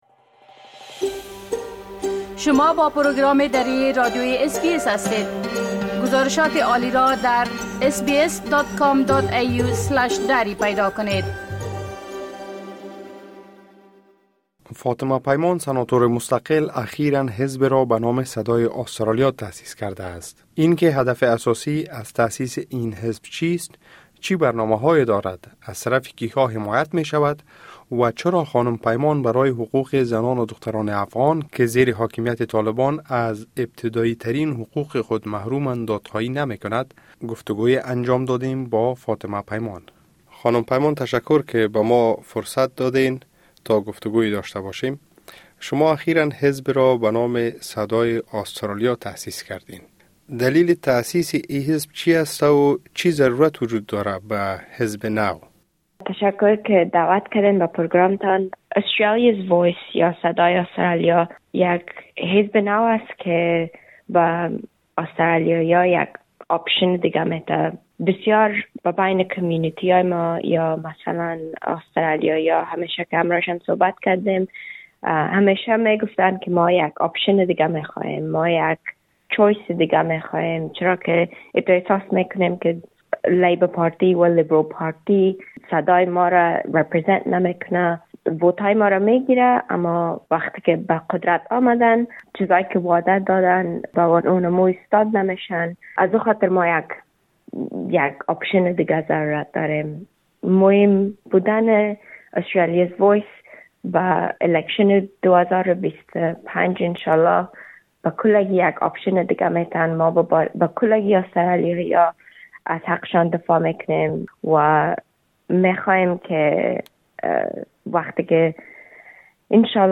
خانم پیمان در گفتگو با اس‌بی‌اس دری به این سوالات پاسخ داده است.